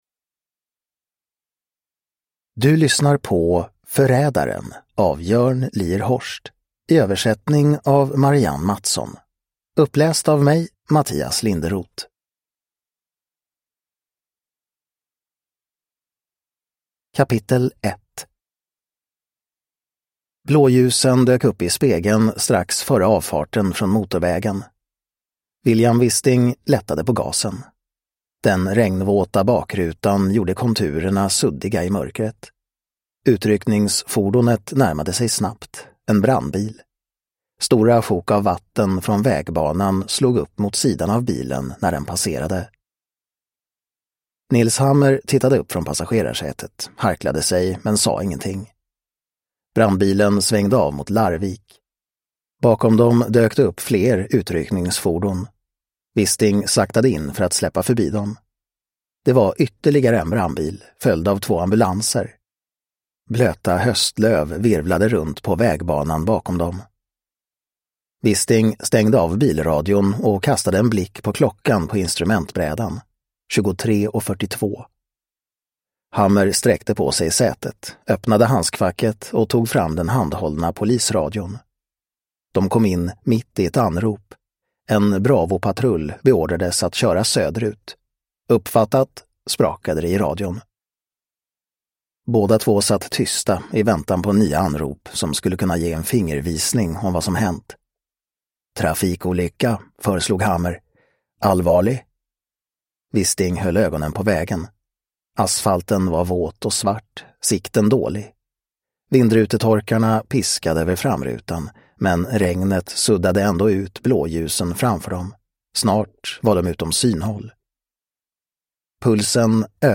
Förrädaren – Ljudbok